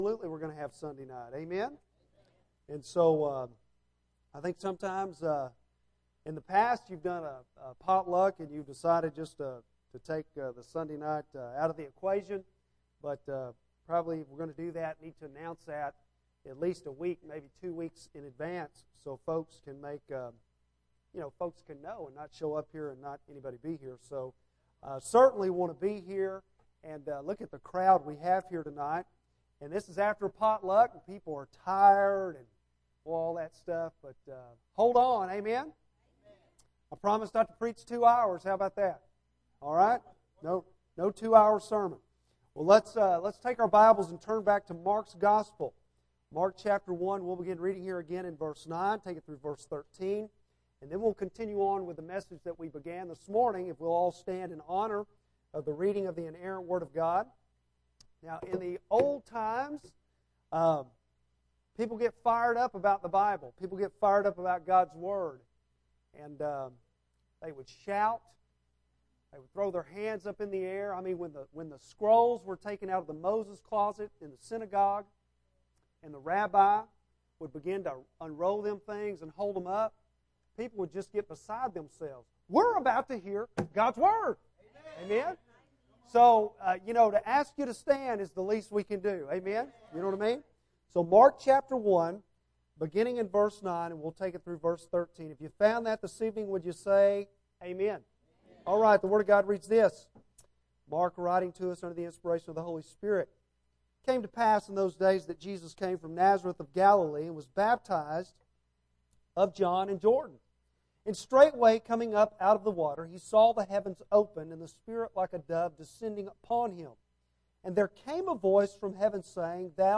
Bible Text: Mark 1:9-13 | Preacher